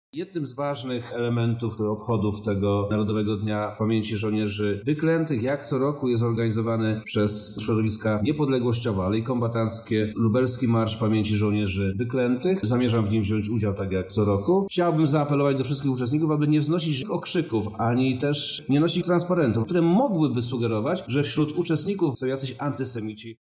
Marsz  – mówi wojewoda Przemysław Czarnek